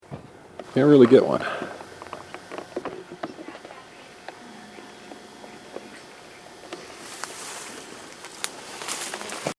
Near the end of the tape I heard a very, very faint sound, almost inaudible.
This is where I shut the camera off, moved, and started it again. You may need to turn it up a little bit.
No other sounds except for the birds overhead.